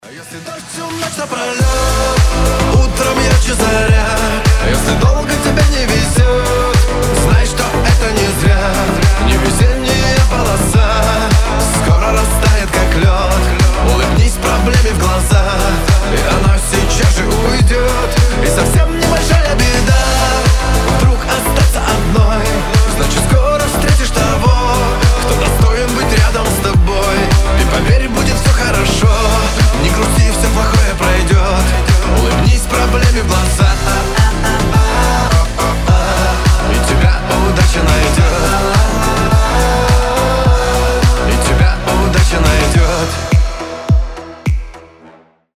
• Качество: 320, Stereo
позитивные
вдохновляющие
добрые